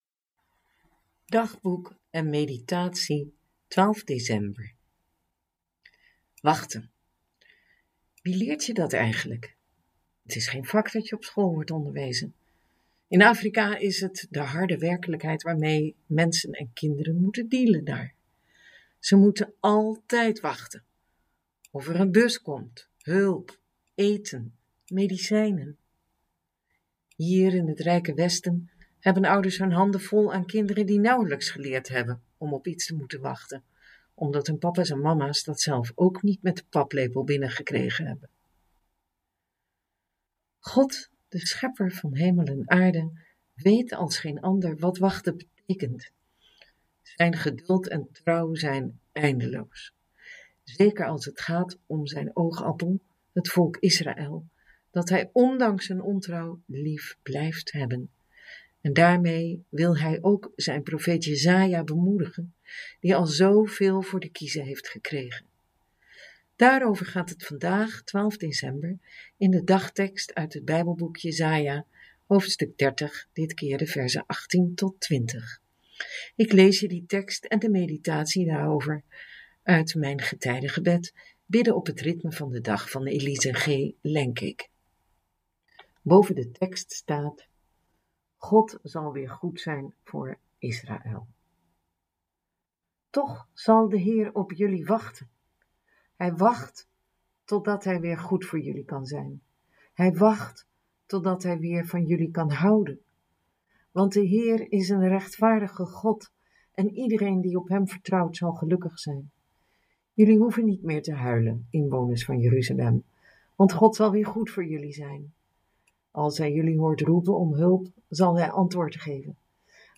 Daarover gaat het vandaag, 12 december, in de dagtekst uit het bijbelboek Jesaja, hoofdstuk 30 de verzen 18-20. Ik lees je die tekst en de meditatie daarover uit Mijn getijdengebed – Bidden op het ritme van de dag van Elise G. Lengkeek.